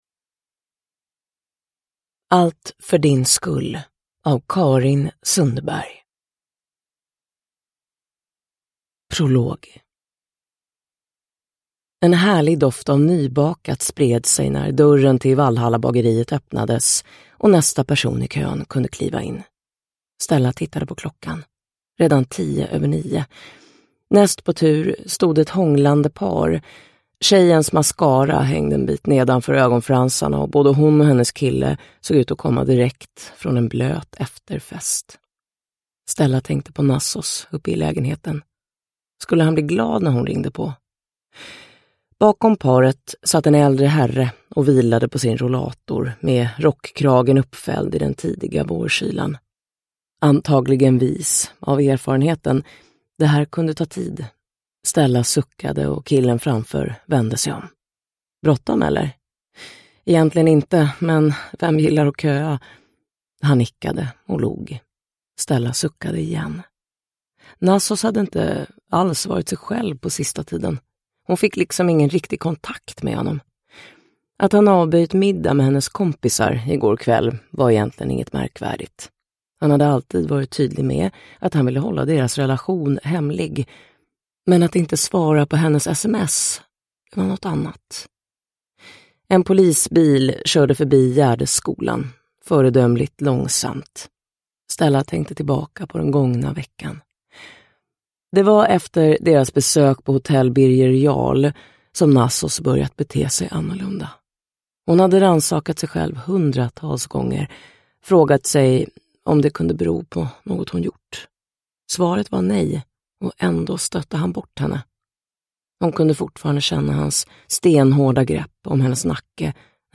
Allt för din skull – Ljudbok – Laddas ner